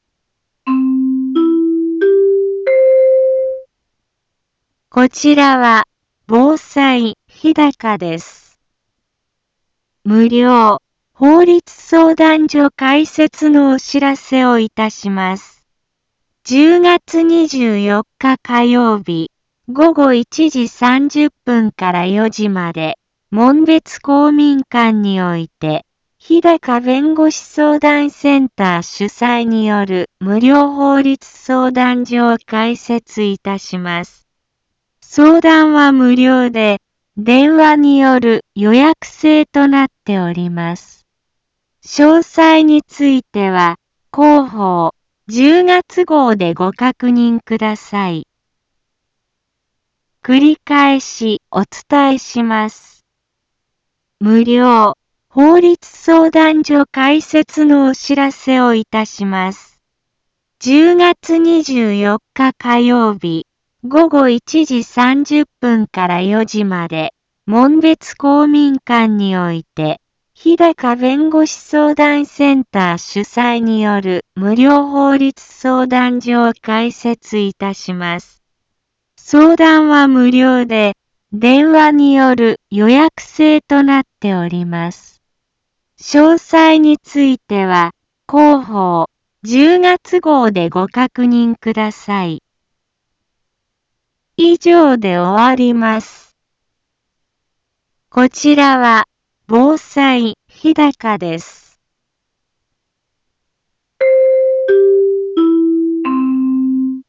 一般放送情報
Back Home 一般放送情報 音声放送 再生 一般放送情報 登録日時：2023-10-23 10:03:46 タイトル：無料法律相談会のお知らせ インフォメーション： 無料法律相談所開設のお知らせをいたします。 10月24日火曜日午後1時30分から4時まで、門別公民館において、日高弁護士相談センター主催による無料法律相談所を開設いたします。